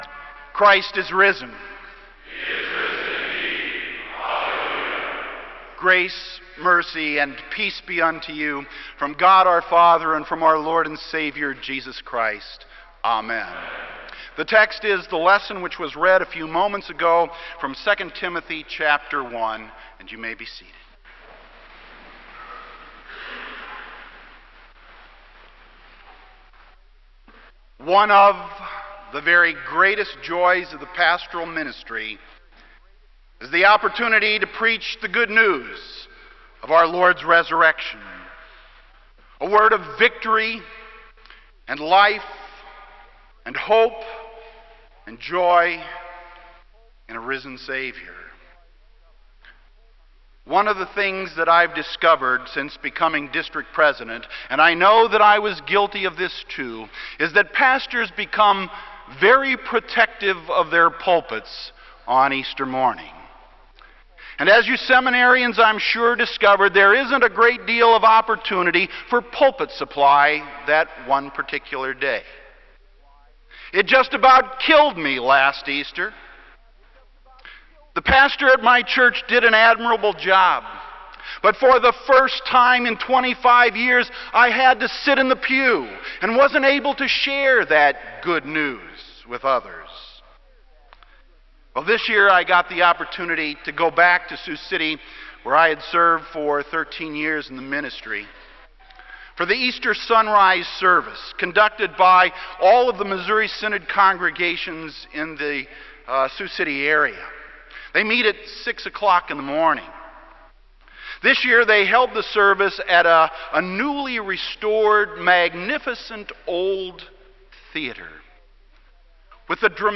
Kramer Chapel Sermon - April 10, 2002